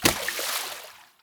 Water_splash_big_2_2.ogg